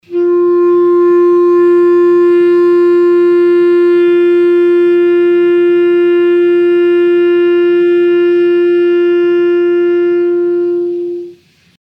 interactive-fretboard / samples / clarinet / F4.mp3